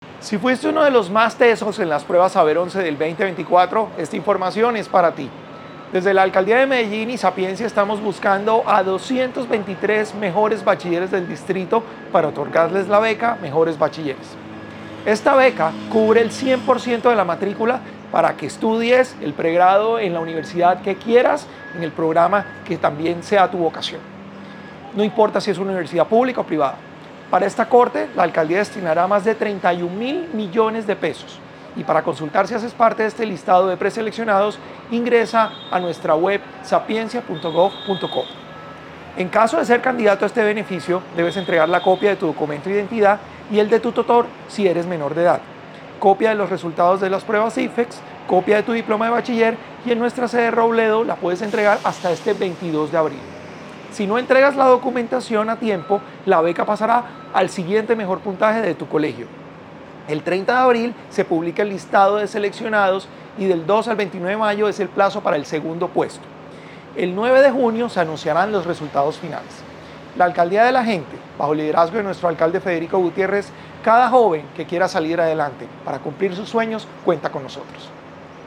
Palabras de Salomón Cruz Zirene, director general de Sapiencia